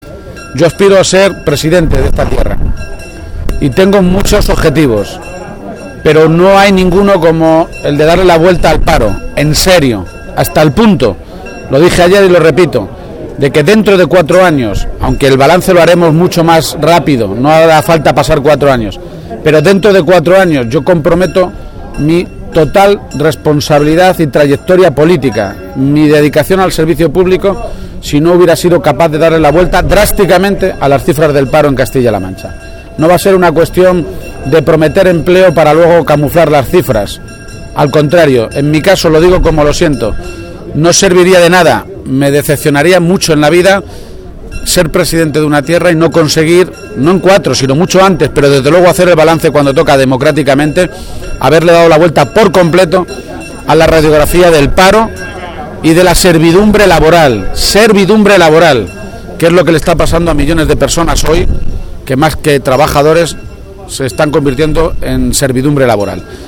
García-Page, que realizó estas declaraciones durante la tradicional Romería del Valle de Toledo, volvió a reiterar el compromiso adoptado ayer en un acto público en Bolaños de Calatrava, de que si es elegido presidente de Castilla-La Mancha su continuidad en la política quedaría condicionada a “darle la vuelta drásticamente a las cifras del paro y a esa servidumbre laboral que están sufriendo miles de trabajadores, que hoy cobran la mitad y trabajan más de lo que hacían antes”.
Cortes de audio de la rueda de prensa